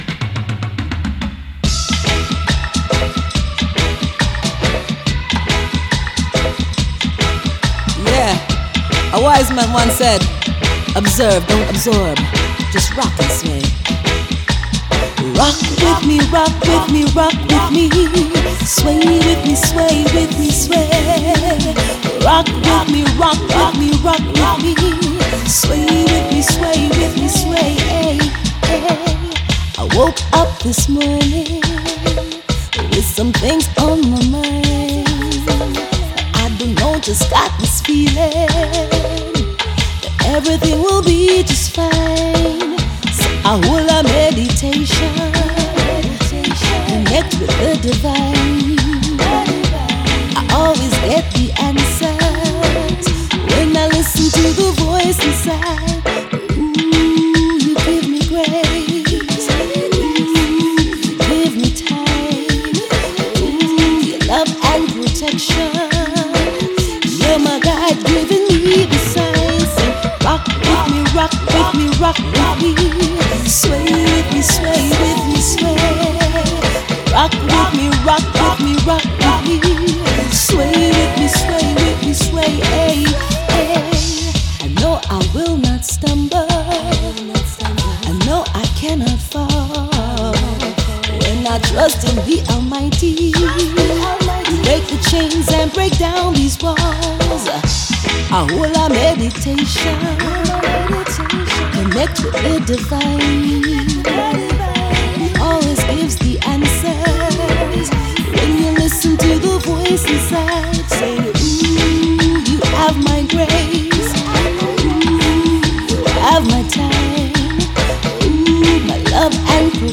Lead Vocal Full Length Stem
Harmony 1 Full Length Stem
Dry / Dub Echo / Echo / LoFi / PlateVerb and Spring Reverb
Genre:Reggae
Tempo Range: 140 bpm
Key: E Major
Full Length Vocal Stems / Loops and Phrases + FX Versions